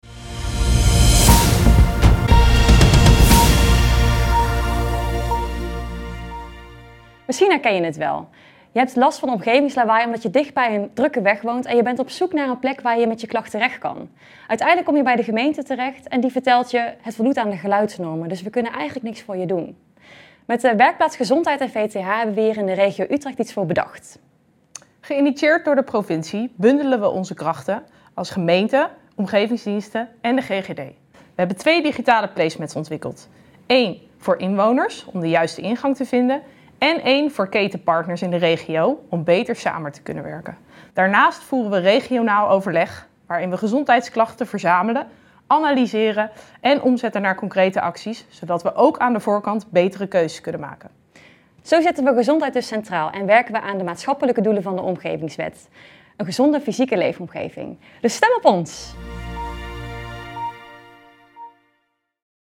Pitch Utrecht